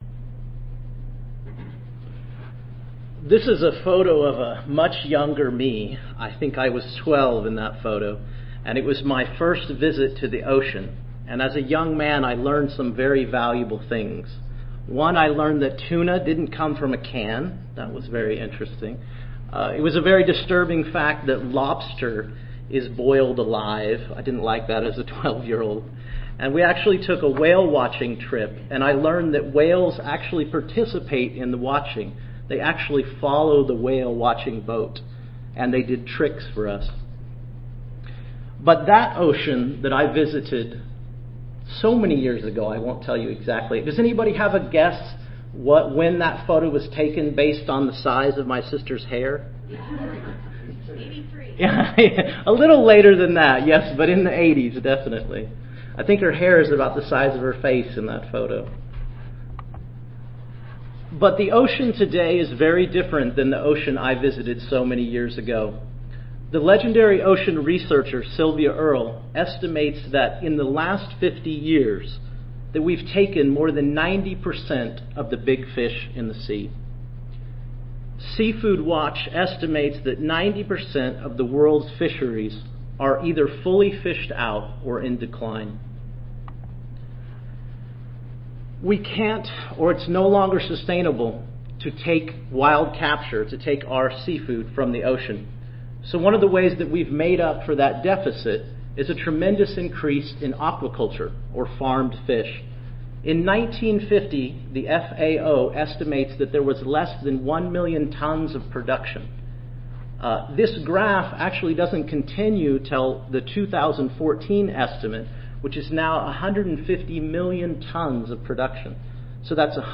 2015 ASHS Annual Conference: Undergraduate Student Oral Competition
Recorded Presentation